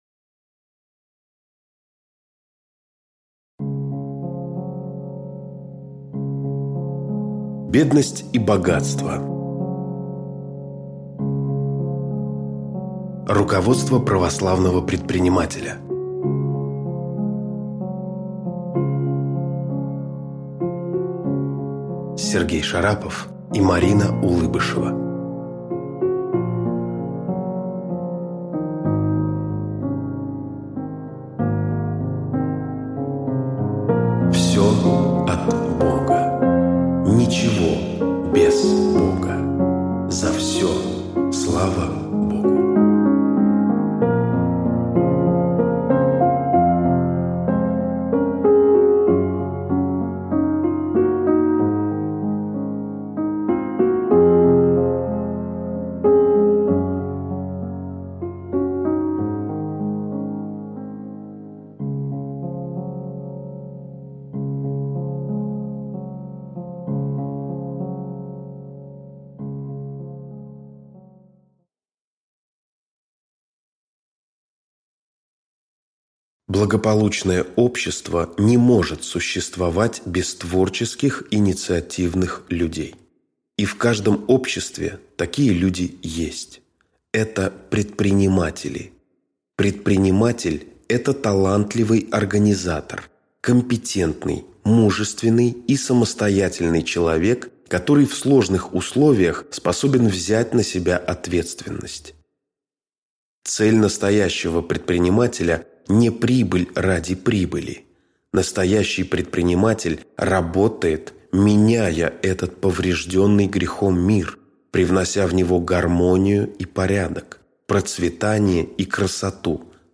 Аудиокнига Бедность и богатство. Руководство православного предпринимателя | Библиотека аудиокниг